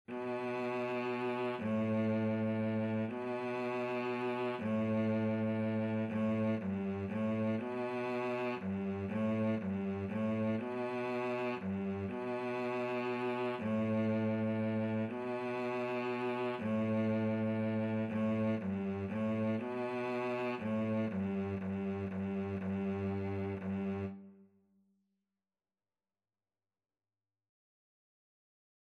3/4 (View more 3/4 Music)
G3-B3
Beginners Level: Recommended for Beginners
Cello  (View more Beginners Cello Music)
Classical (View more Classical Cello Music)